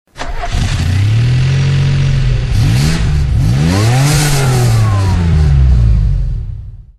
engineon.mp3